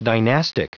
Prononciation du mot dynastic en anglais (fichier audio)
Prononciation du mot : dynastic